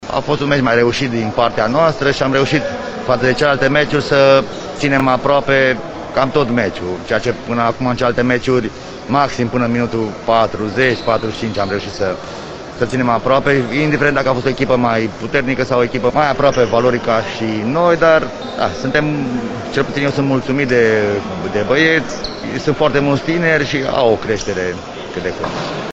La declarațiile din finalul meciului